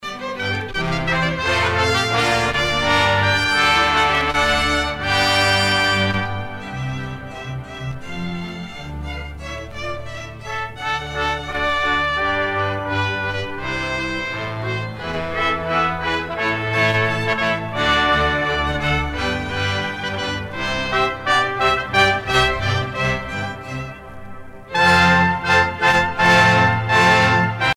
Canzon VII et VIIII - Toni à 12 voix en 3 choeurs